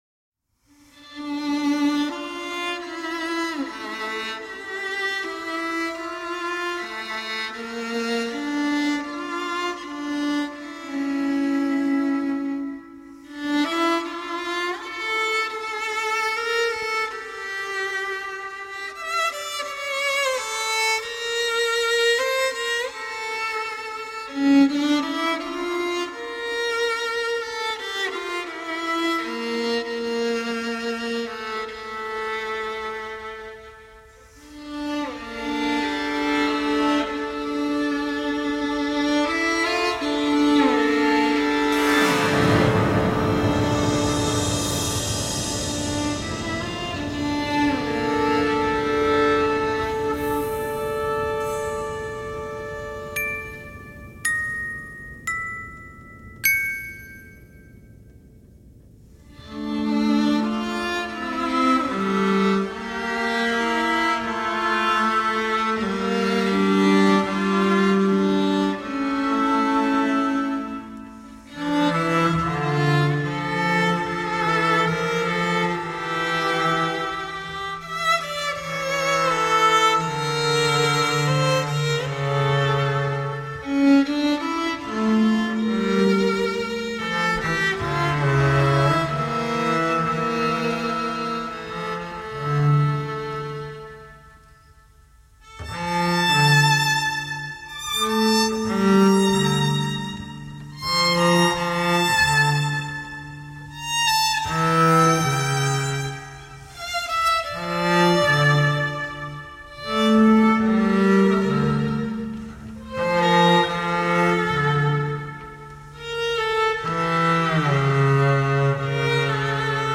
Haunting string piece